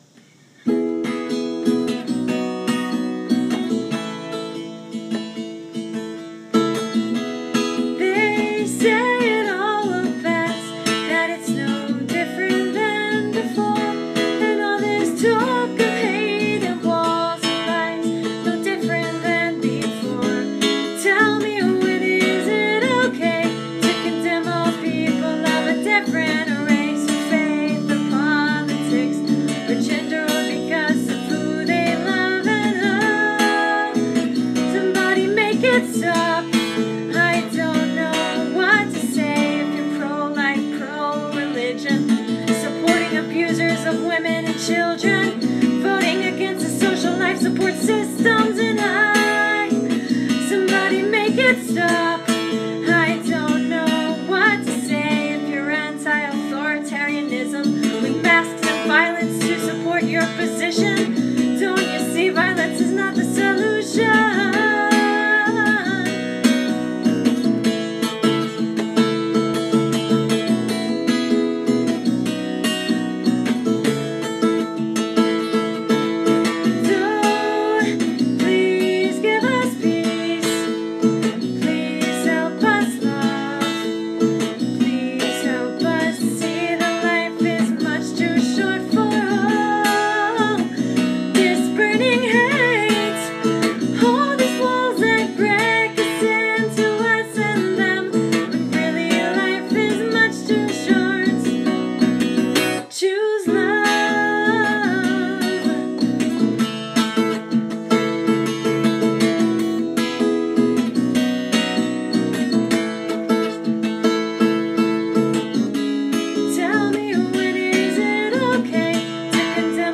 — It’s a protest song, with new video coming soon :-)